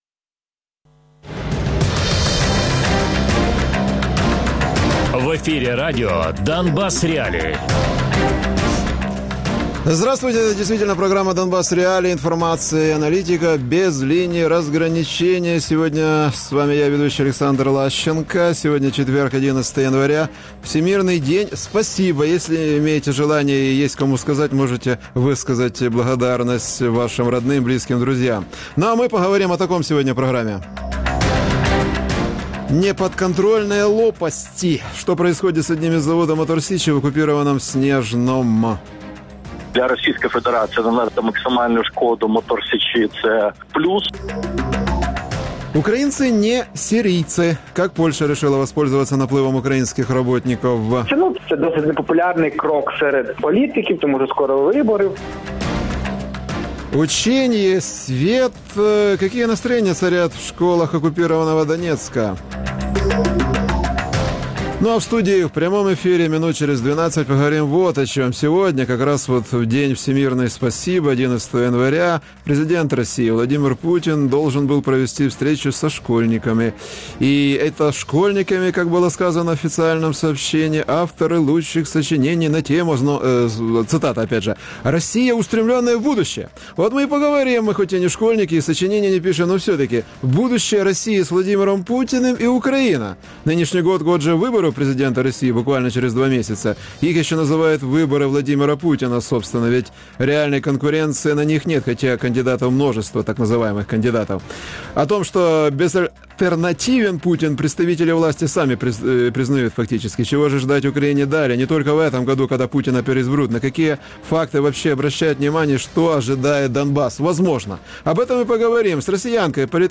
Оглядач «Нового часу» Радіопрограма «Донбас.Реалії»